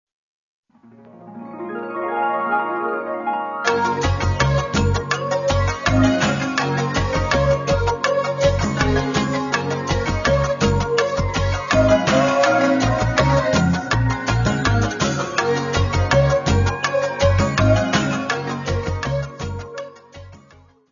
voz e teclas
guitarra eléctrica
clarinete baixo
saxofone soprano.
: stereo; 12 cm
Área:  Novas Linguagens Musicais